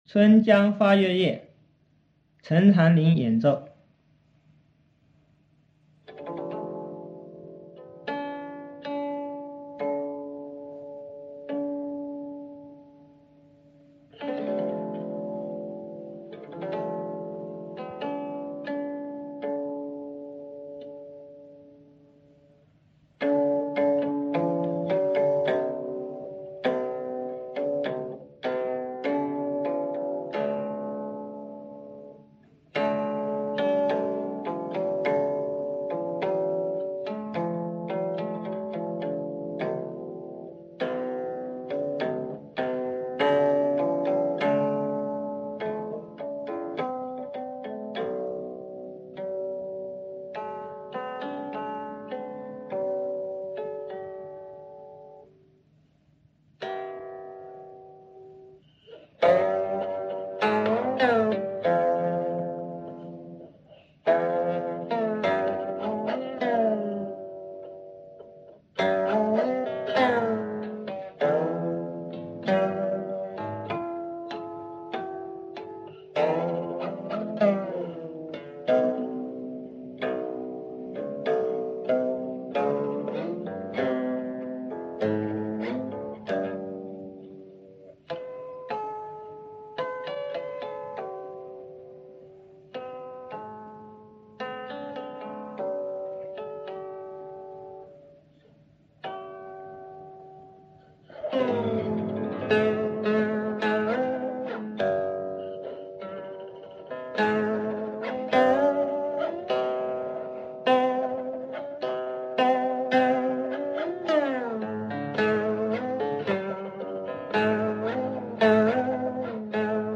qin
excellent joueur de qin